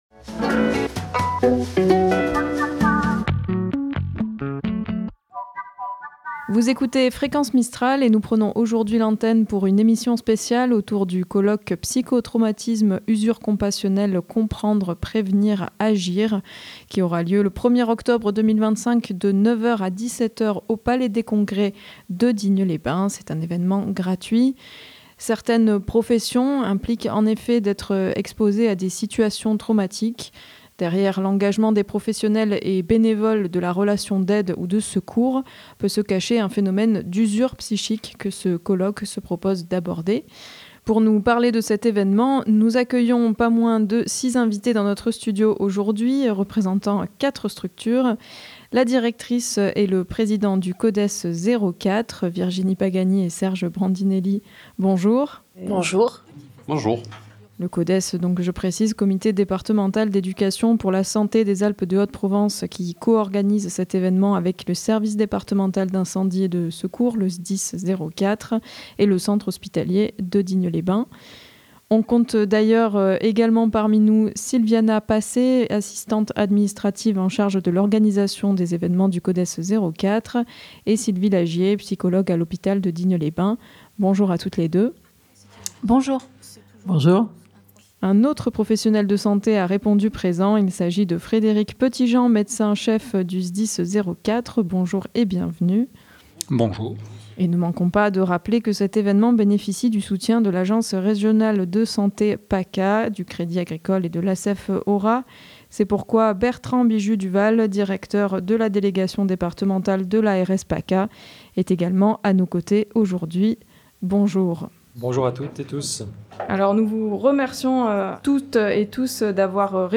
Emission colloque psychotraumatisme et usure compassionnelle.mp3 (80.11 Mo)
Des conférencier·ères reconnu·es dans les domaines du psychotraumatisme, l'usure compassionnelle, le traumatisme vicariant ainsi que le trouble de stress post traumatique interviendront pour faire découvrir certaines pratiques et proposer des rencontres avec des partenaires de terrain. Pour nous en parler au micro de Fréquence Mistral Digne